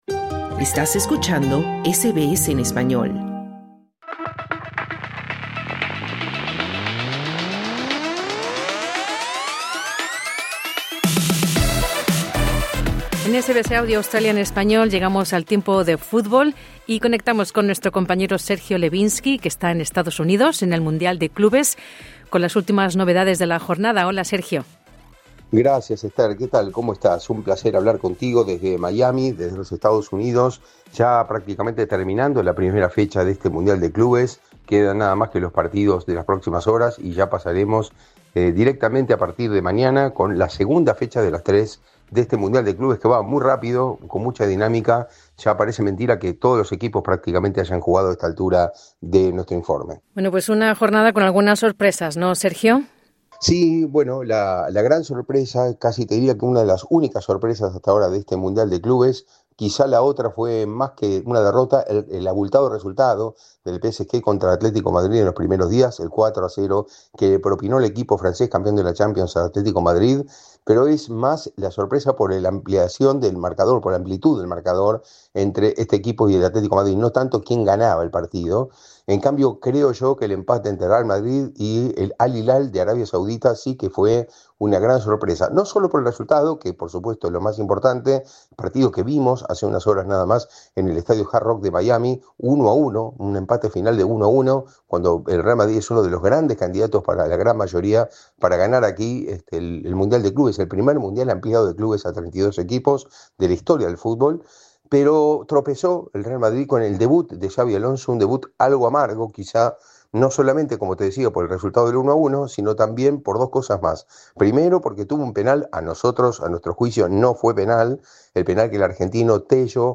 Real Madrid solo empató 1-1 con el Al Hilal, mientras que el Manchester City derrotó por 2-0 al Wydad AC en el Mundial de Clubes de la FIFA. Escucha el reporte con nuestro enviado especial a Estados Unidos